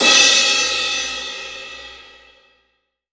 Index of /90_sSampleCDs/AKAI S6000 CD-ROM - Volume 3/Crash_Cymbal1/18_22_INCH_CRASH
DRY 18CRS1-S.WAV